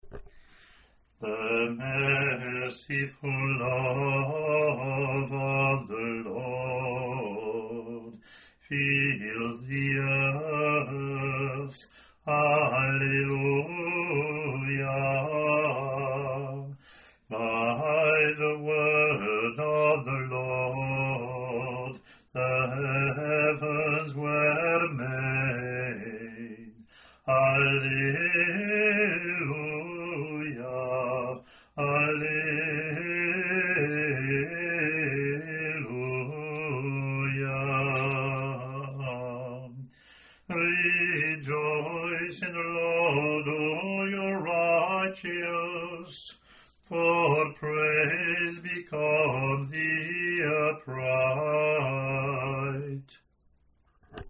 Download proper in English (same Gregorian mode): EA04 proper ENG PW
English antiphon and verseLatin antiphon and verse, Gloria Patri)